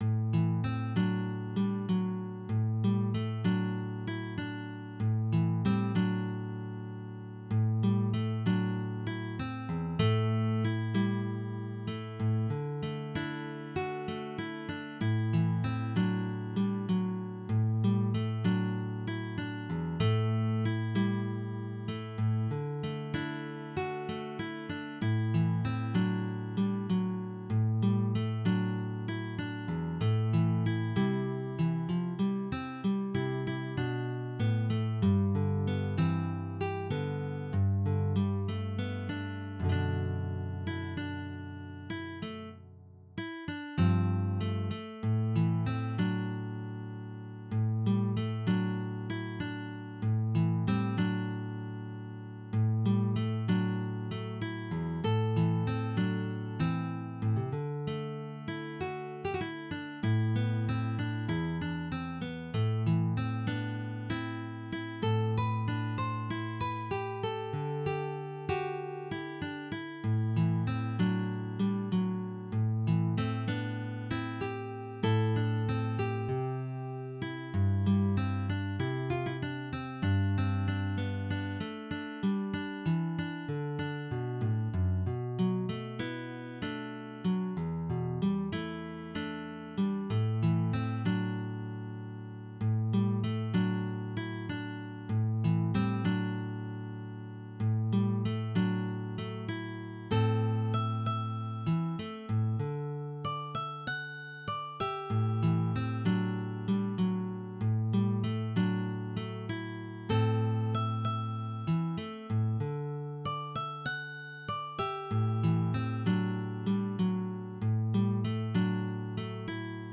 DIGITAL SHEET MUSIC - CLASSIC GUITAR SOLO
Classic Guitar, Traditional Ballad